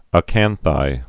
(ə-kănthī)